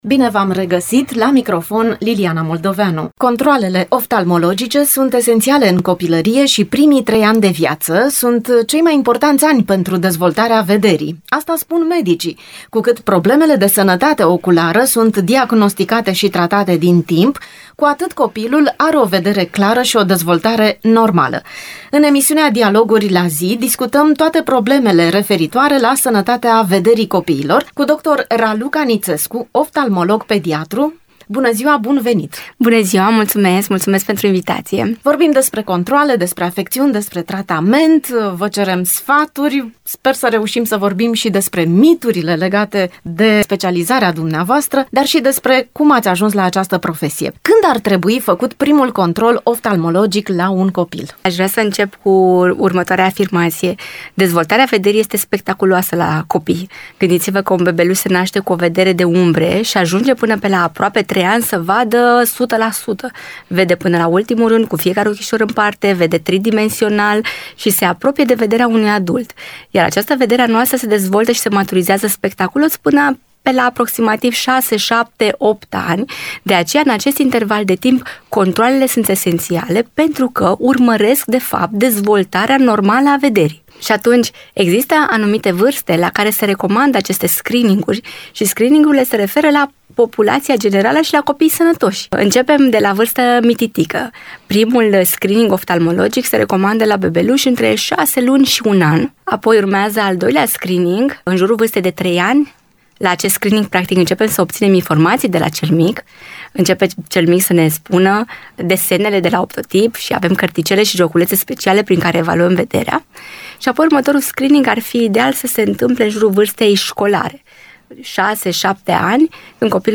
aflăm de la oftalmologul pediatric